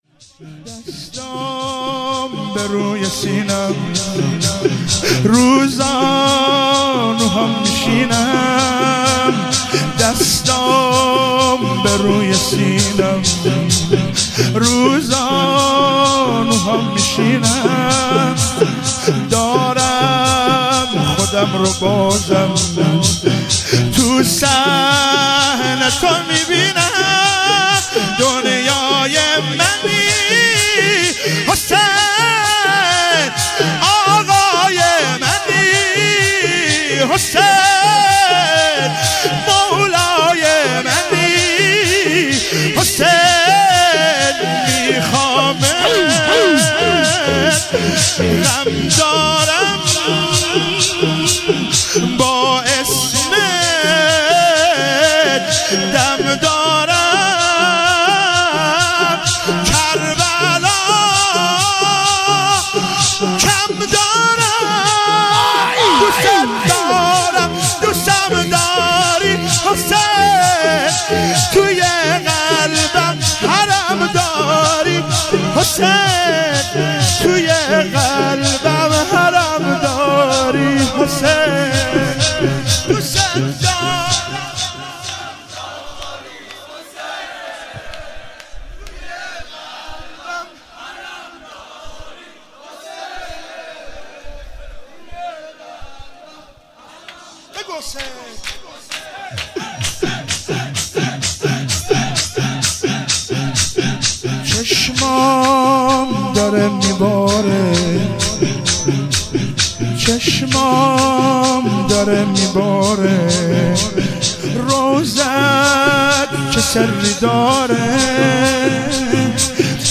شور|دستام بروی سینم